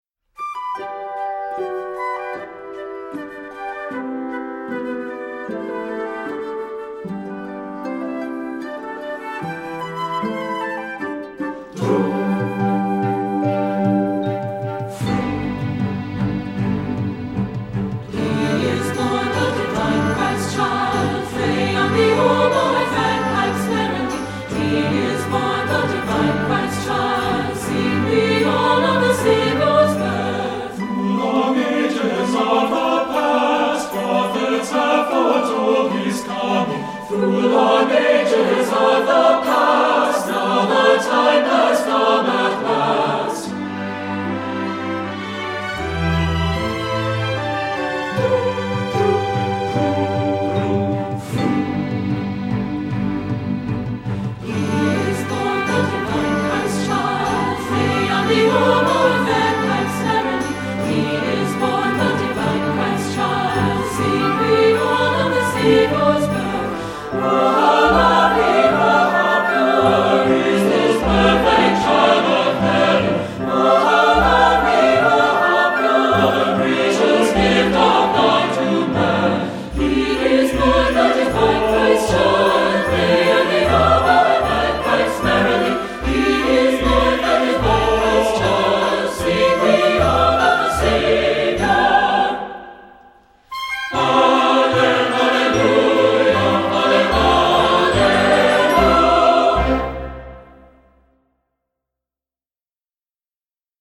Composer: Traditional French
Voicing: SAB